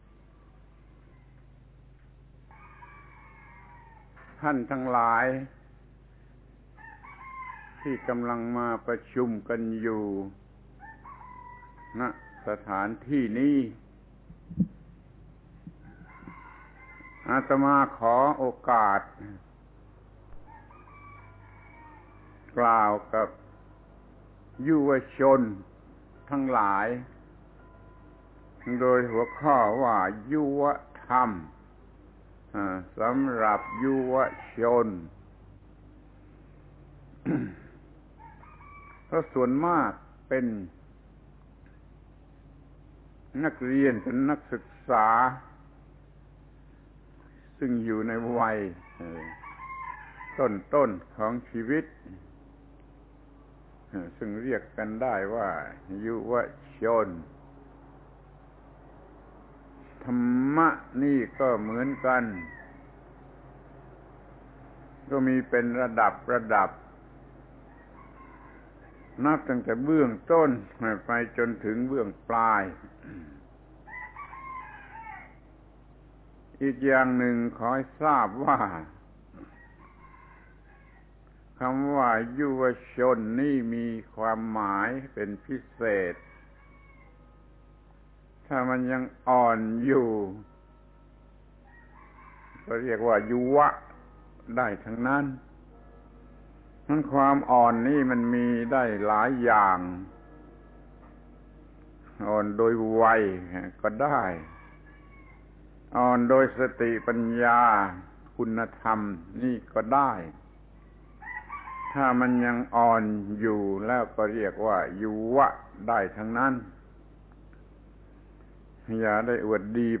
พระธรรมโกศาจารย์ (พุทธทาสภิกขุ) - บรรยายแก่คณะนักเรียนนักศึกษา ยุวธรรมสำหรับยุวชน